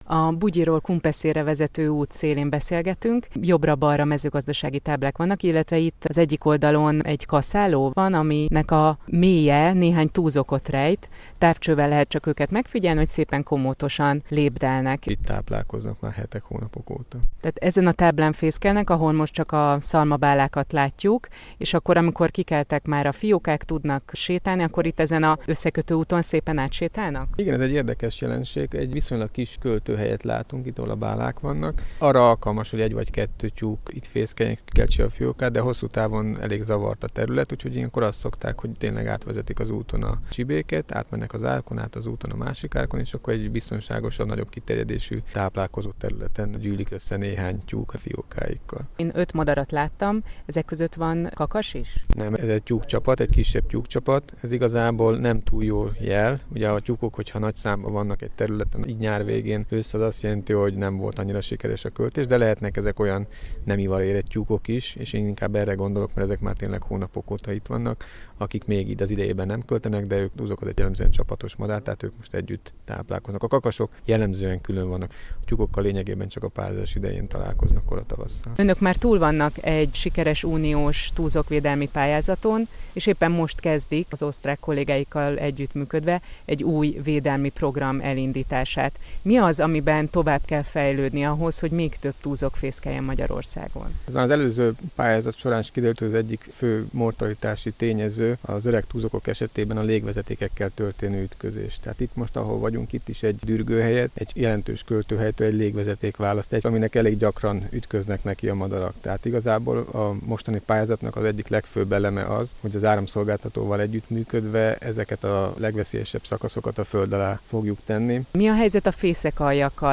Radio report MR1 Kossuth Radio 24.05.2017